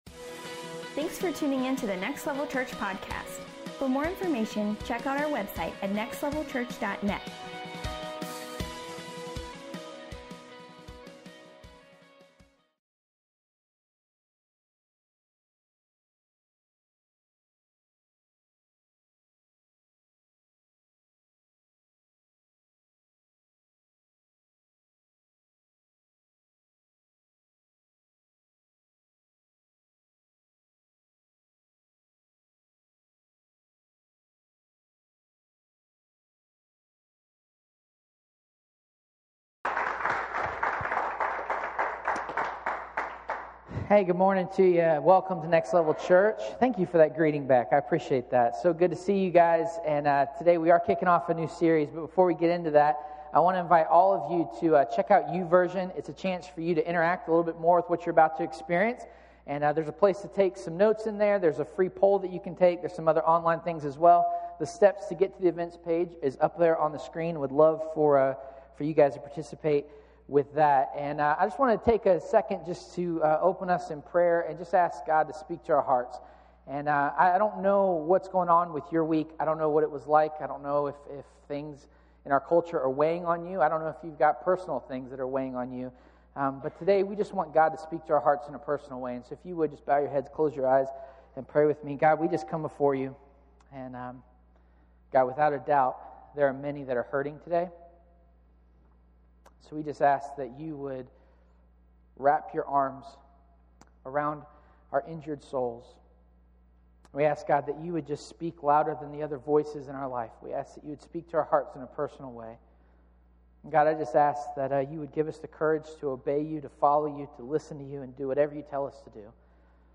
Modern Day Parables Service Type: Sunday Morning « God and our Country Movies